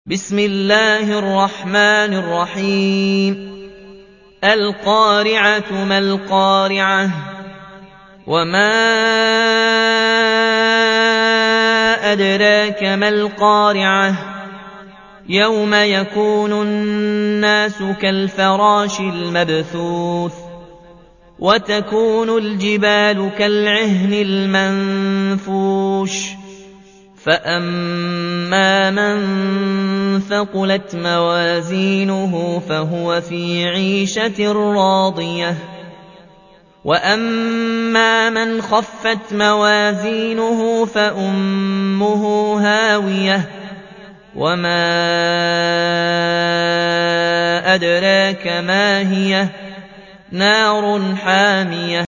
تحميل : 101. سورة القارعة / القارئ ياسين الجزائري / القرآن الكريم / موقع يا حسين